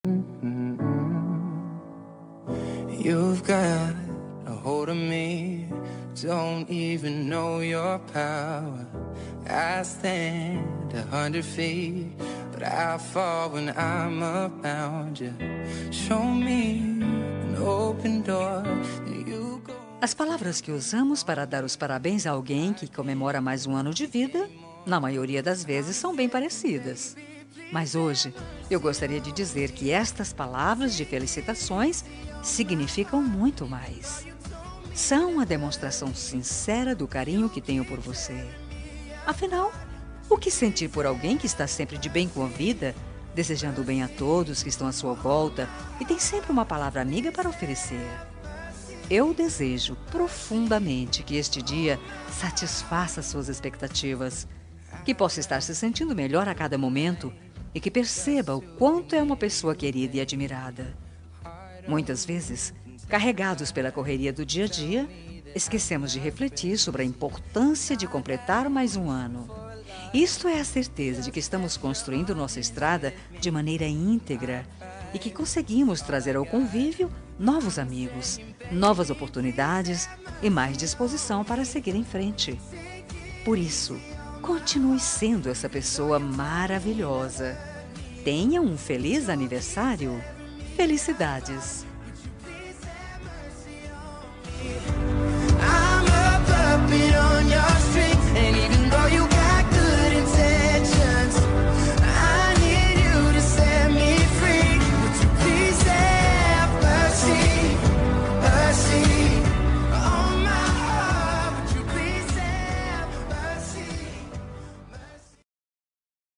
Aniversário de Ficante – Voz Feminina – Cód: 8877